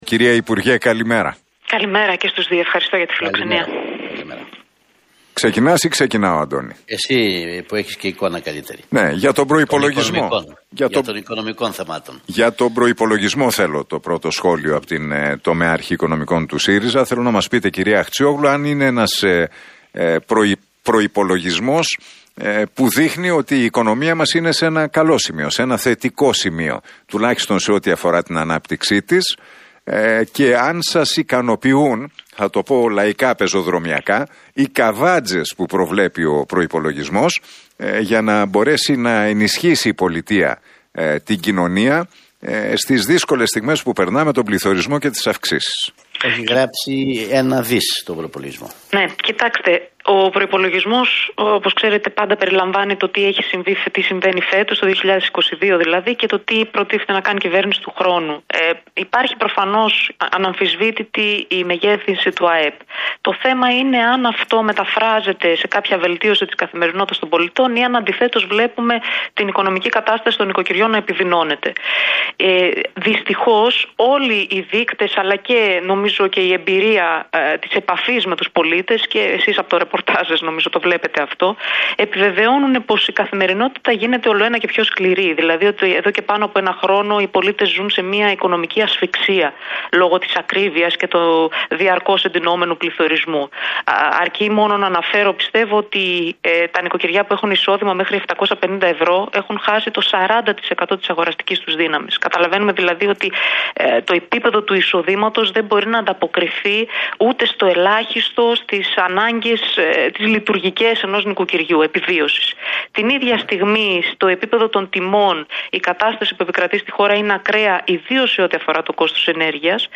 Αχτσιόγλου στον Realfm 97,8: Οι πολίτες ζουν σε μια οικονομική ασφυξία